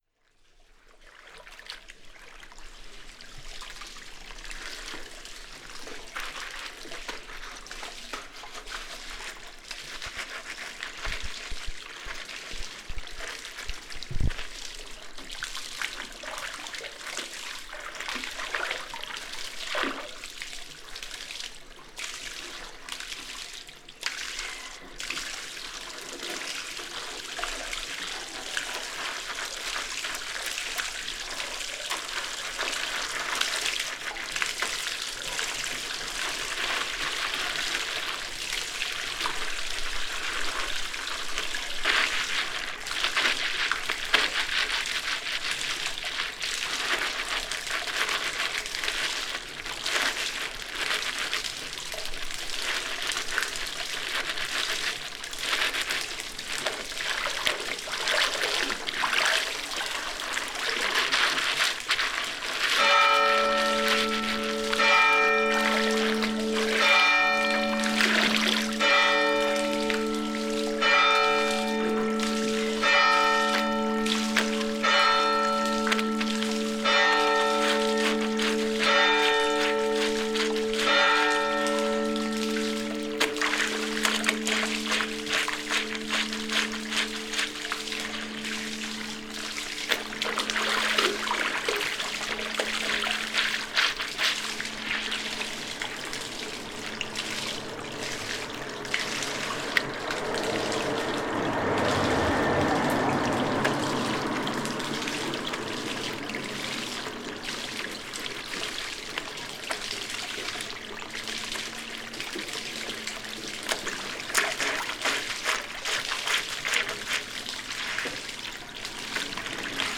Paisagem sonora de lavagem de roupa em lavadouro comunitário no largo da Fonte da Igreja em Campo de Madalena, Campo a 8 Março 2016.
Numa manhã fria de Março uma senhora lavava roupa à mão no lavadouro comunitário, eram quase 10h00.
NODAR.00561 – Campo: Lavagem de roupa em lavadouro comunitário no largo da Fonte da Igreja em Campo de Madalena + Sino